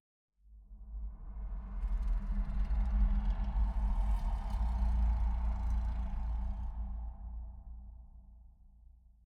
Minecraft Version Minecraft Version snapshot Latest Release | Latest Snapshot snapshot / assets / minecraft / sounds / ambient / nether / basalt_deltas / twist3.ogg Compare With Compare With Latest Release | Latest Snapshot